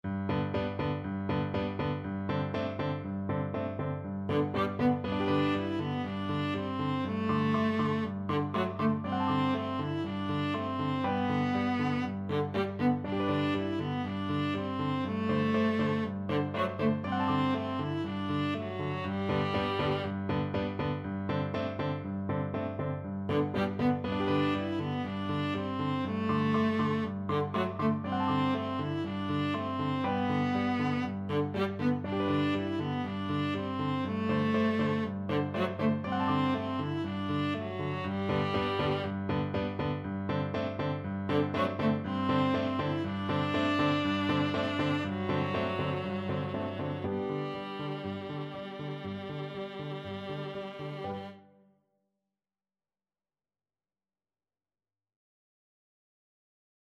Viola
2/4 (View more 2/4 Music)
G major (Sounding Pitch) (View more G major Music for Viola )
Lively, enthusiastic! =c.120
Traditional (View more Traditional Viola Music)